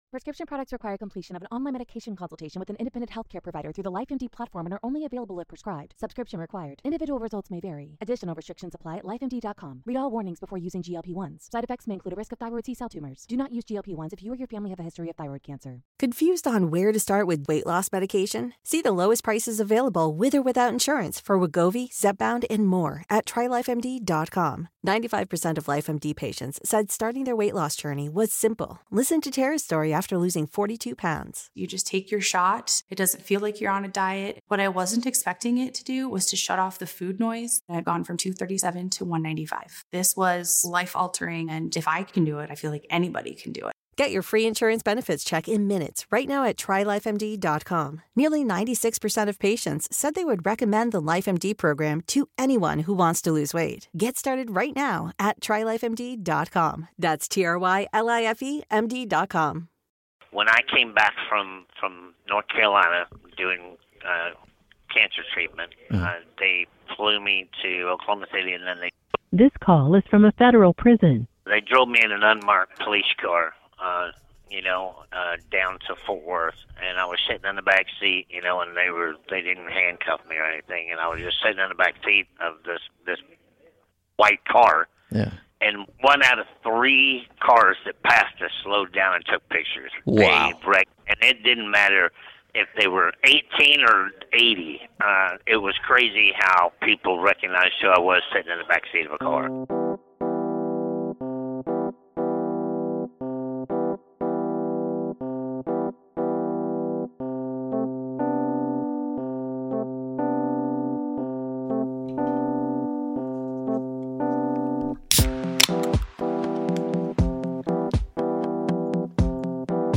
Comedy, Stand-up
Joe Exotic calls into Laugh with Me from the Federal Medical Center in Fort Worth, Texas where Joe is serving a 21 year sentence.